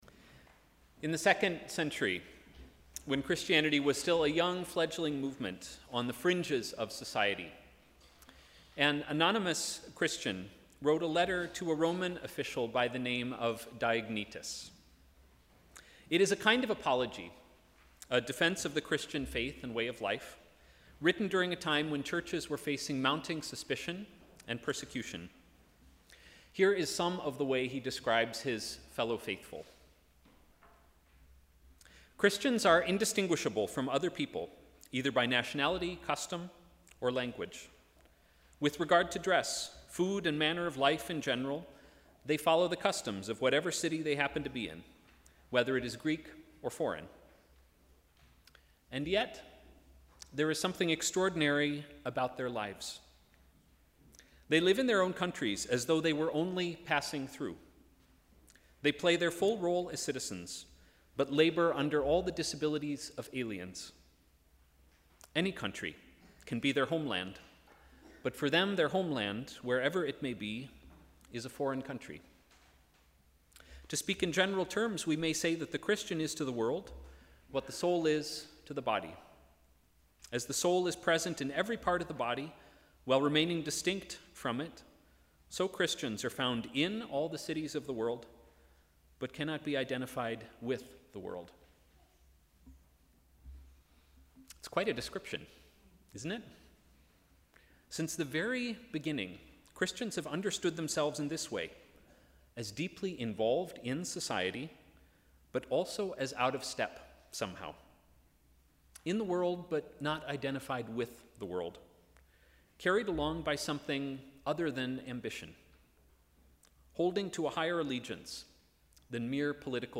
Sermon: ‘The body of Christ’